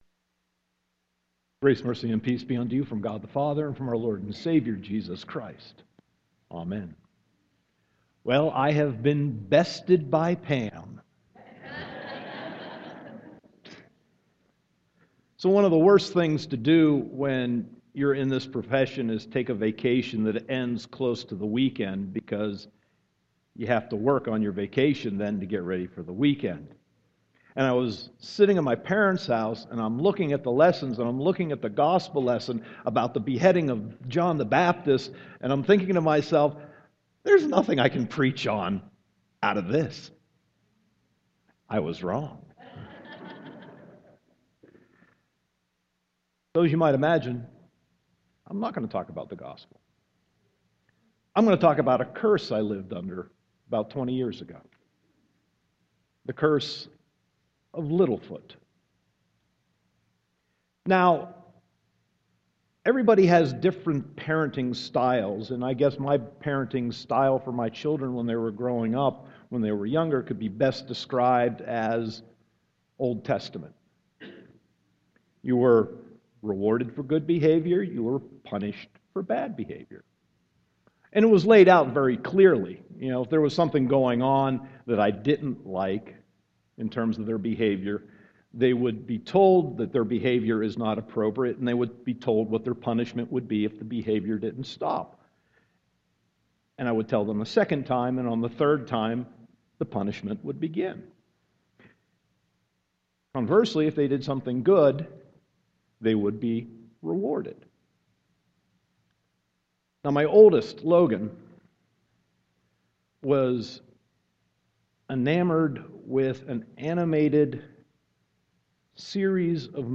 Sermon 7.12.2015